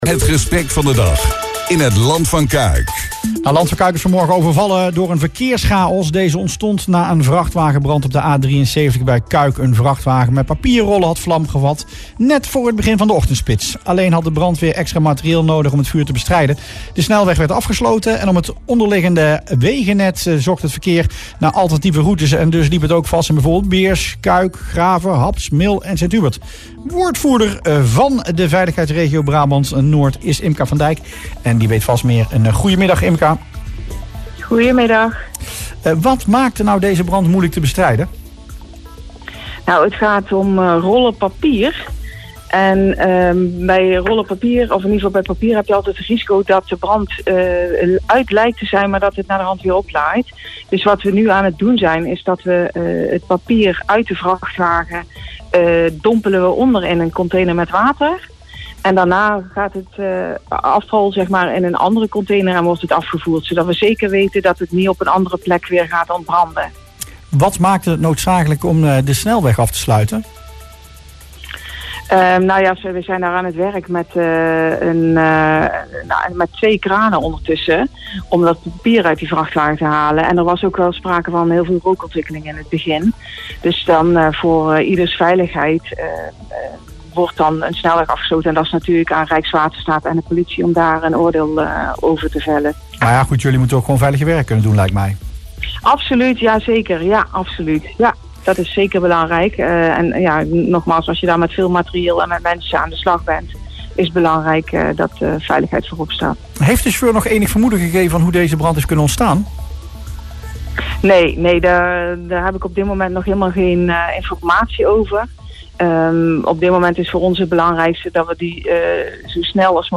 in Rustplaats Lokkant